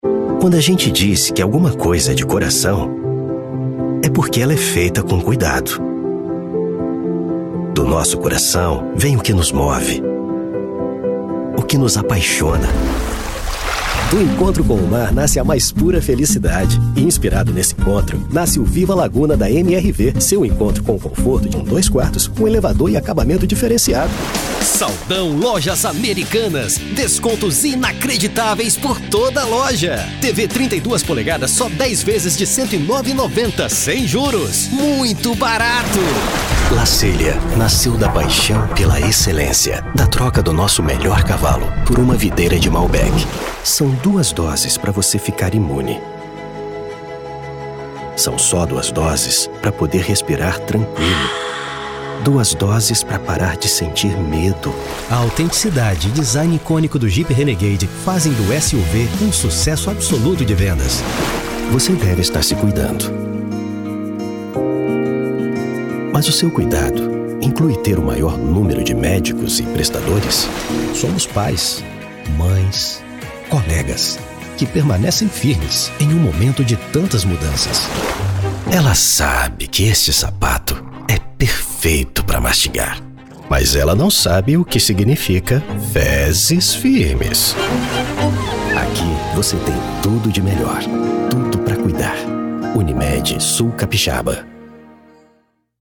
Sprechprobe: Werbung (Muttersprache):
Commercials